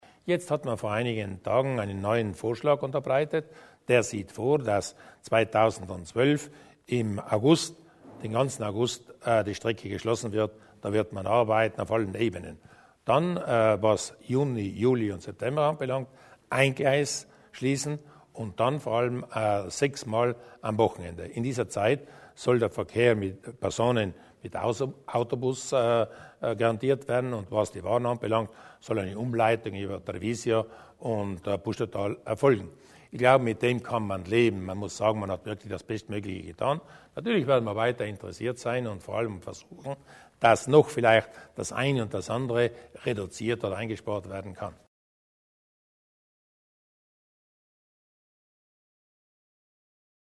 Landeshauptmann Durnwalder zu den Behinderungen auf der Brennerlinie